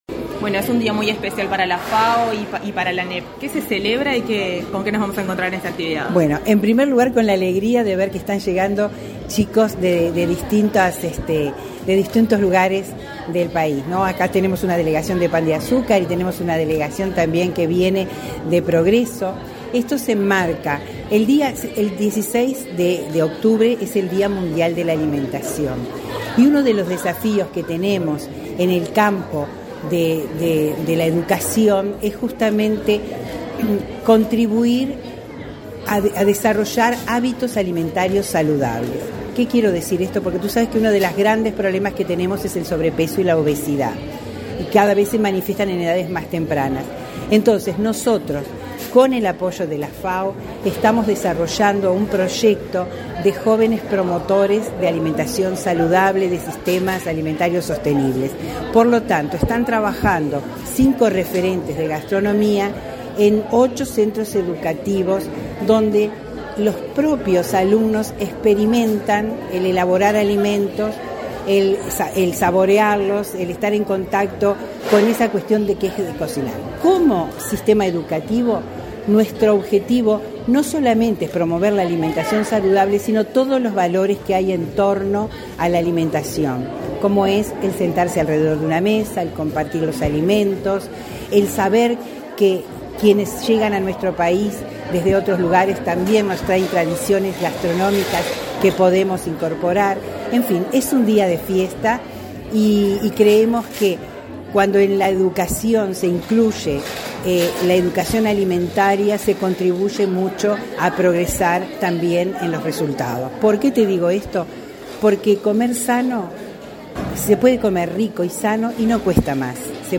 Entrevista a la directora de Derechos Humanos de Codicen, Gloria Canclini
La Dirección de Derechos Humanos del Consejo Directivo Central (Codicen) de la Administración Nacional de Educación Pública (ANEP) organizó un encuentro por el Día Mundial de la Alimentación, realizado este 12 de octubre. La titular de la citada dependencia y referente de la ANEP en esta actividad, Gloria Canclini, efectuó declaraciones a Comunicación Presidencial.